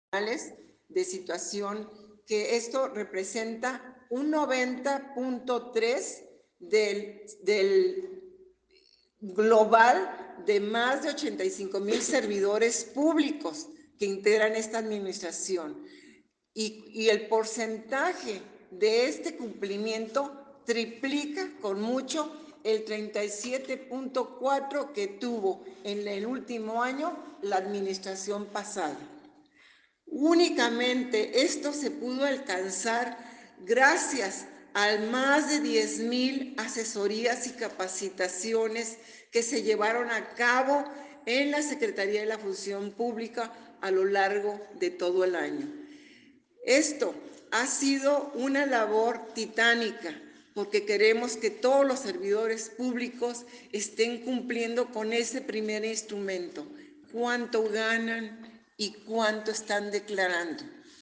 Chihuahua, Chih.- Como parte de la rendición de trabajo de las dependencias estatales en el Informe de Actividades de la gobernadora del Estado, María Eugenia Campos, la titular de la Secretaría de la Función Pública (SFP), María De los Ángeles Álvarez Hurtado, en compañía de la subsecretaria de Asuntos jurídicos, Contrataciones Públicas y Responsabilidad de la dependencia, Flavia Quiñones Chávez y del  subsecretario de Fiscalización de la SFP, Osiel Torres, presentó un avance de resultado realizados durante el año 2023.